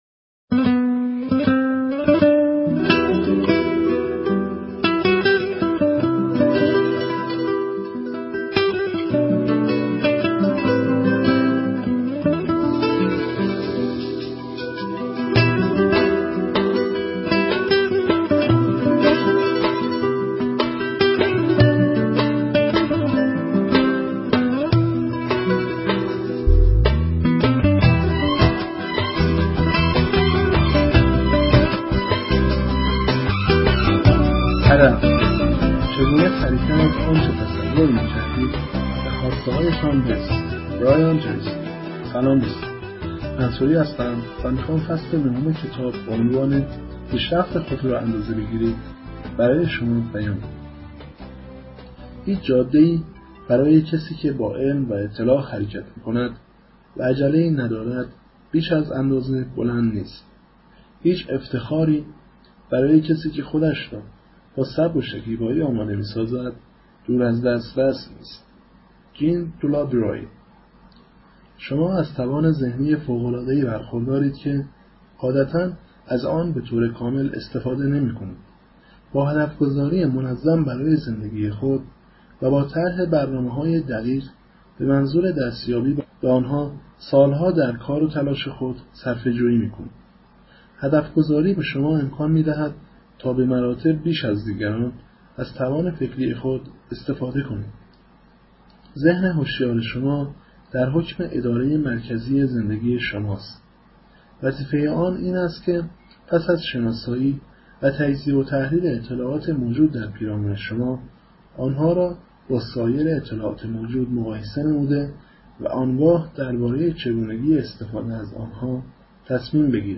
آموزش صوتی/هدف/برایان تریسی/قسمت دهم 94.4.25